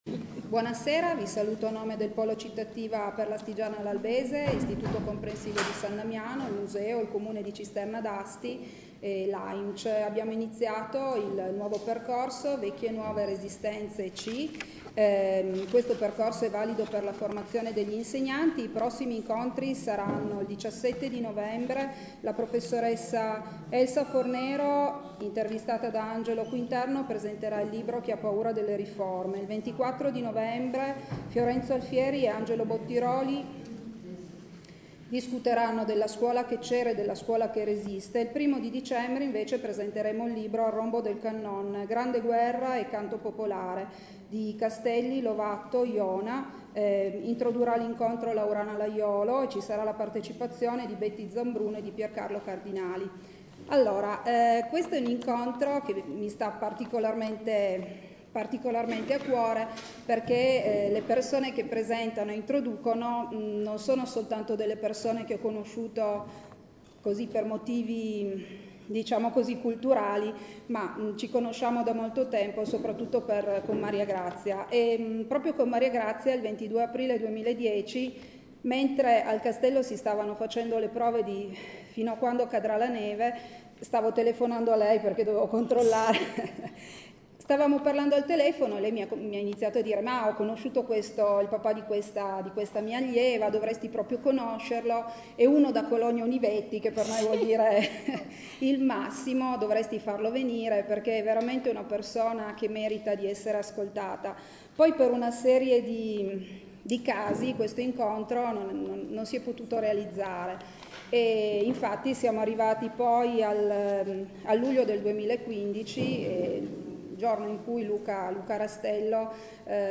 REGISTRAZIONE DELL’INCONTRO IN FORMATO MP3